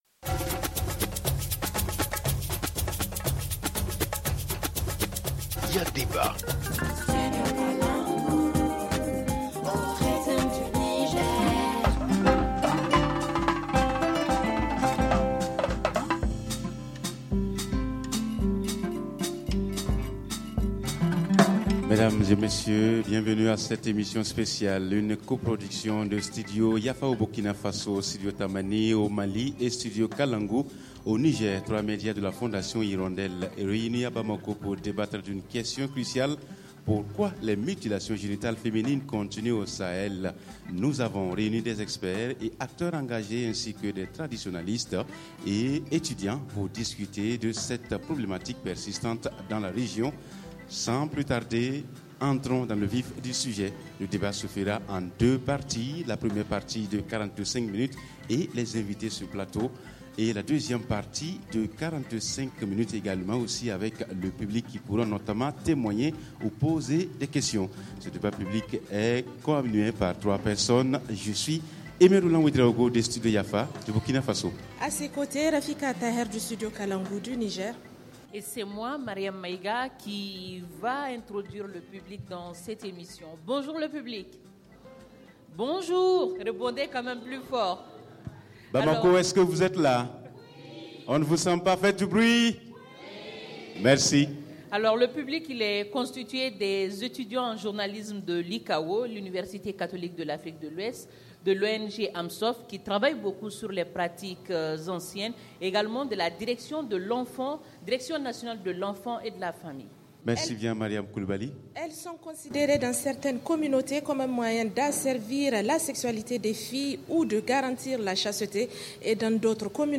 Cette émission est coproduite à Bamako au Mali par trois médias de la fondation.